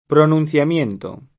pronunciamento [pronun©am%nto] s. m. — anche in forma sp., pronunciamiento [